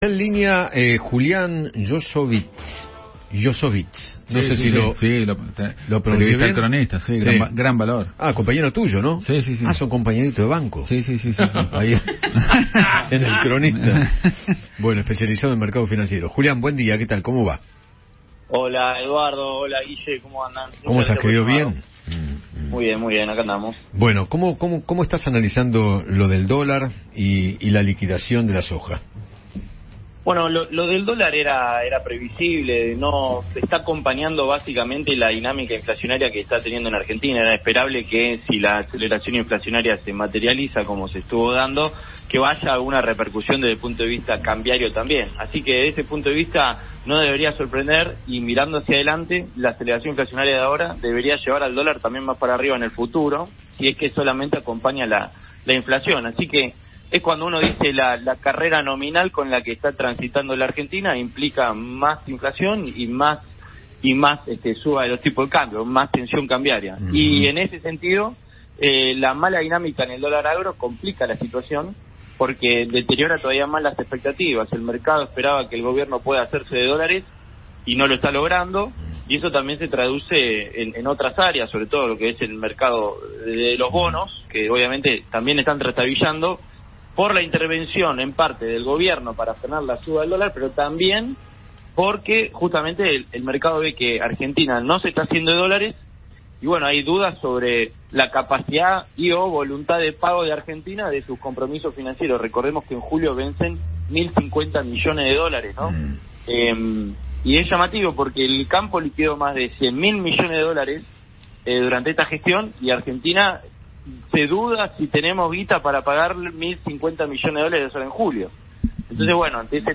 conversó con Eduardo Feinmann sobre la disparada cambiaria y analizó la situación económica del país.